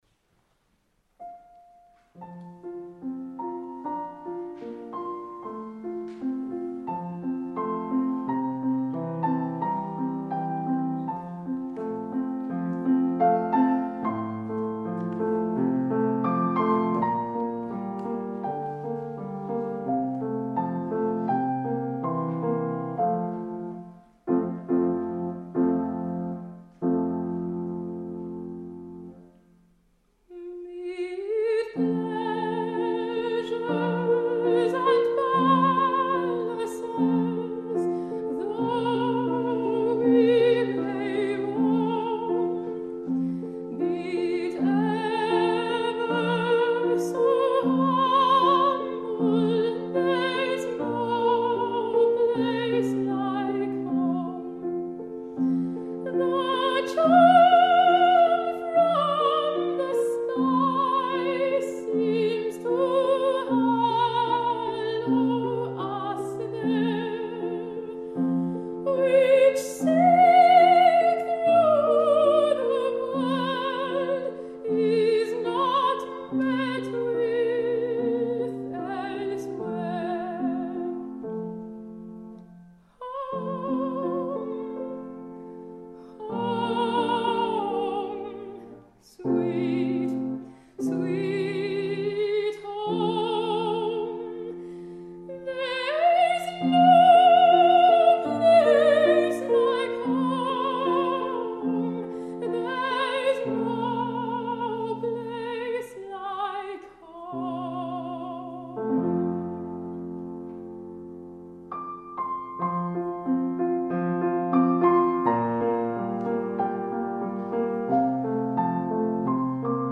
Ara encara fa front a rols de lleugera, però ella és una soprano lírica coloratura posseïdora d’una veu molt bonica, amb un cos que fa preveure una evolució a terrenys més lírics i de consistència vocal i dramàtica més amples.
soprano
piano
The Salon, Melbourne Recital Centre 23 de juliol de 2015